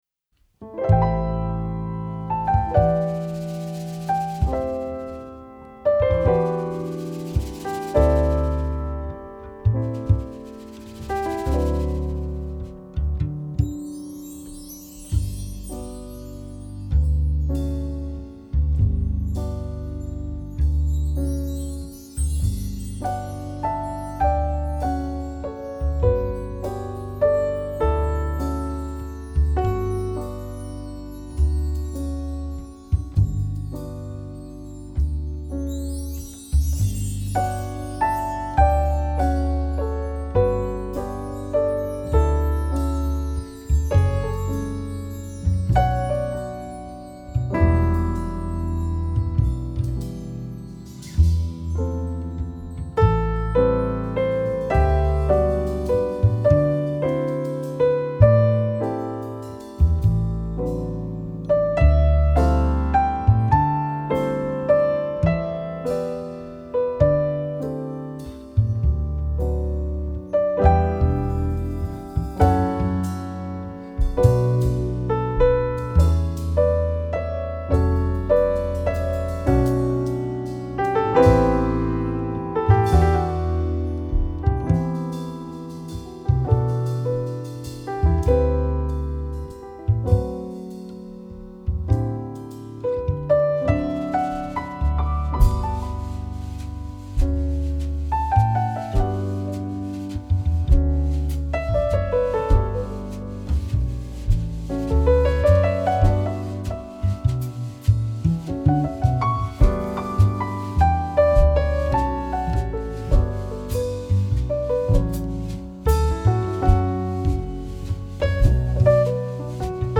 音樂類別 ：爵士樂 ． 爵士三重奏
專輯特色 ：古典，轉化為爵士的無限遐想
好浪漫的音樂